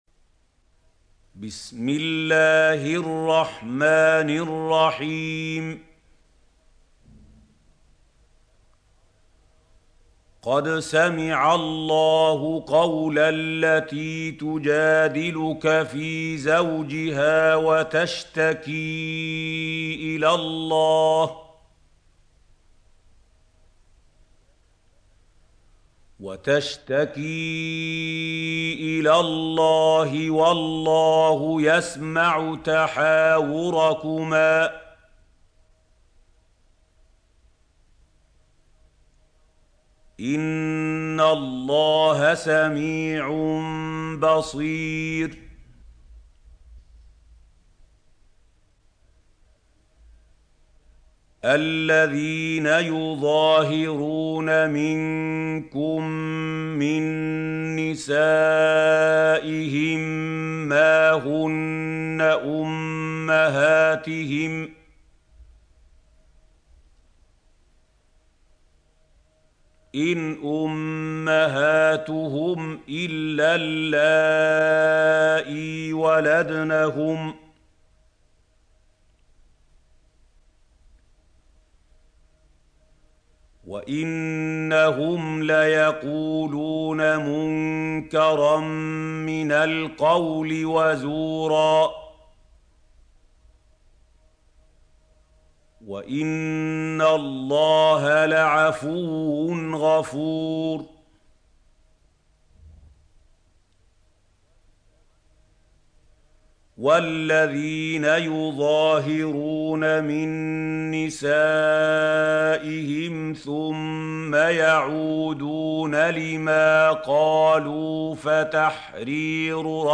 سورة المجادلة | القارئ محمود خليل الحصري - المصحف المعلم